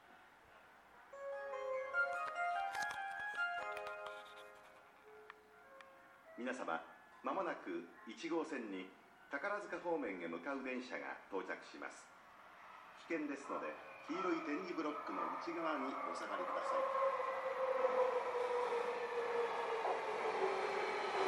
この駅では接近放送が設置されています。
接近放送急行　宝塚行き接近放送です。